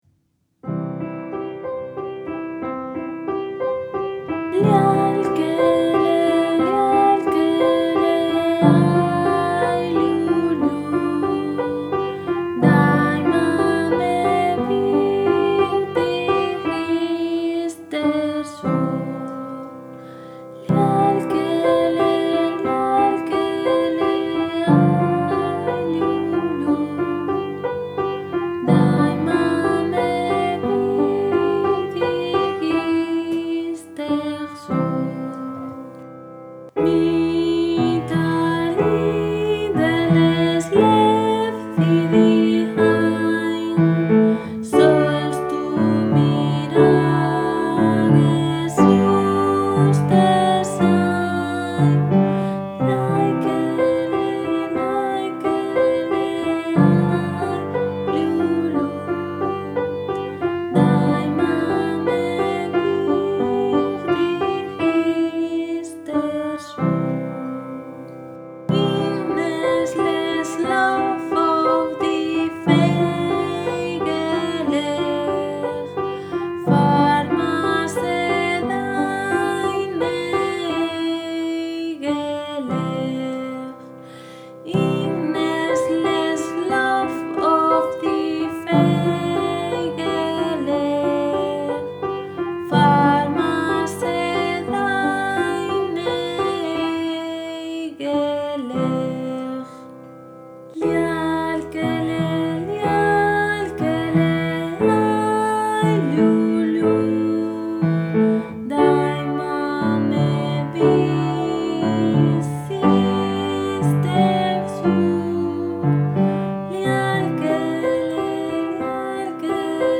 Melodía e acompañamento:
Esta é unha canción de berce: emprégase para durmir os bebés, con sons doces e tranquilos.